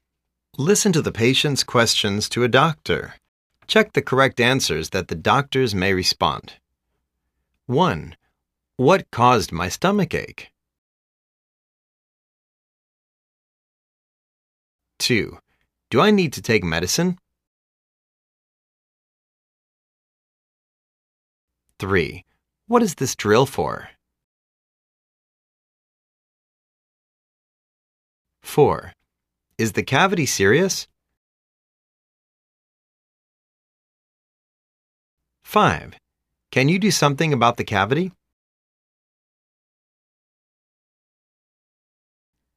Listen to the patients' questions to a doctor.Check the correct answers that the doctors may response.